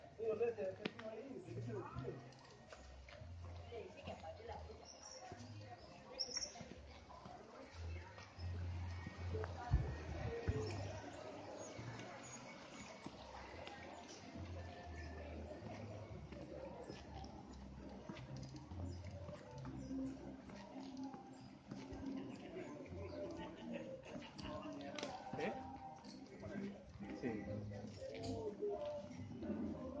描述：Sonido ambiente en Pueblo Pance，zona rural a las afueras de Cali。